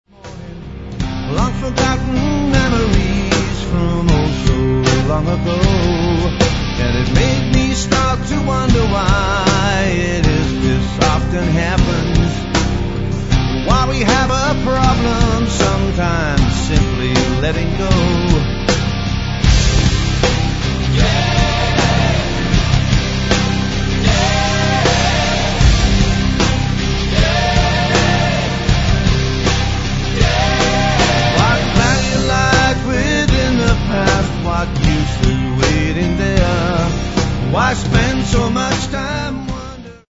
lead vocals, guitar, keyboards, dobro
electric and acoustic guitars
bass guitar
drums
percussion
7-string guitar
acoustic guitar, backing vocals
backing vocals